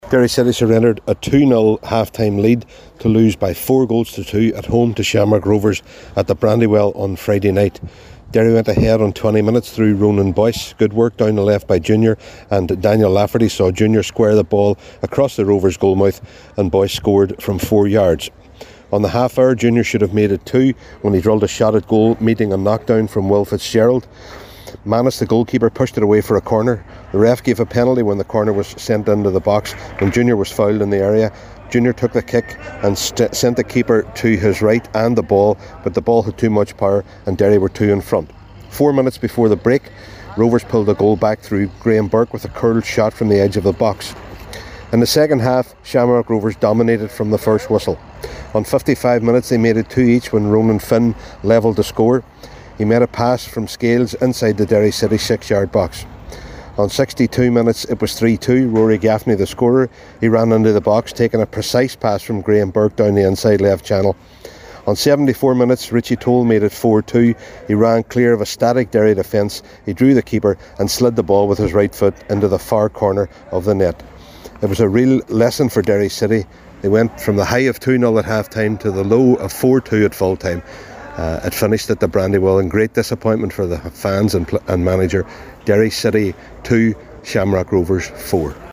reports for Highland Radio Sport…